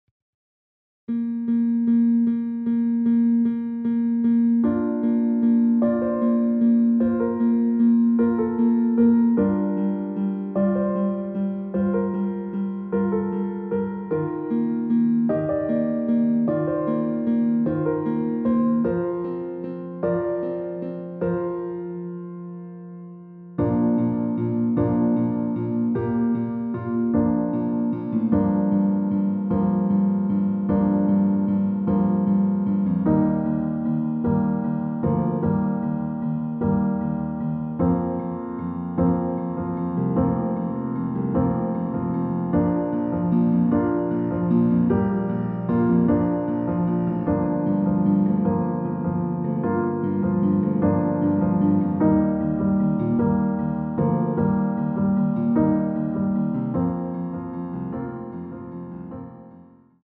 원키에서(-2)내린 MR입니다.
Bb
앞부분30초, 뒷부분30초씩 편집해서 올려 드리고 있습니다.
중간에 음이 끈어지고 다시 나오는 이유는